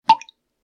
drip.ogg.mp3